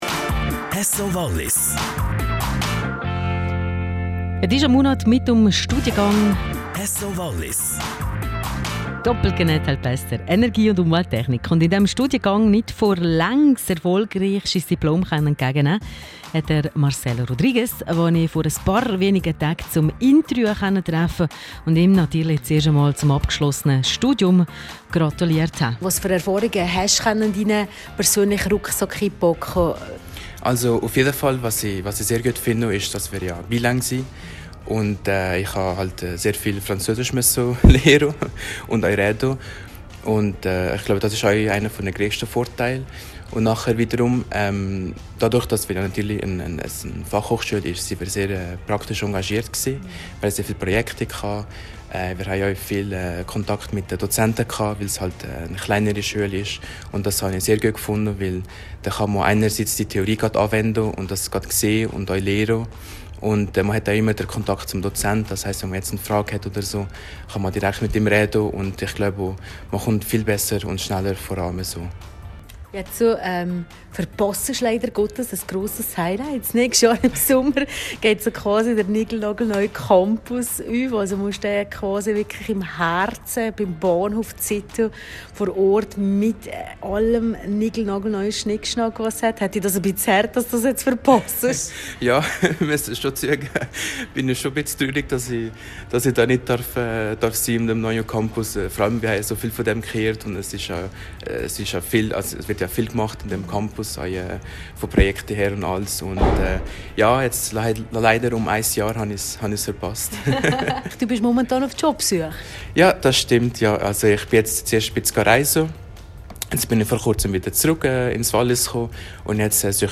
rro-Interview